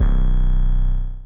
kits/TM88/808s/8.wav at main